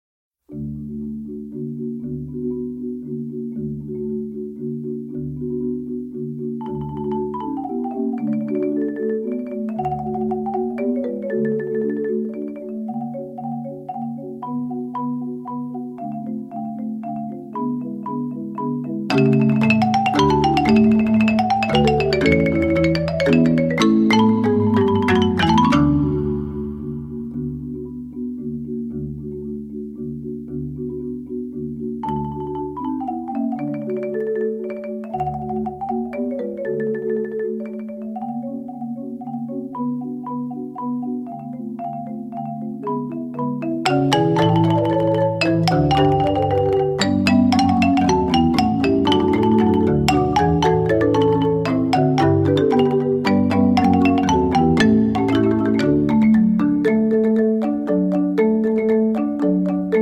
chamber percussion group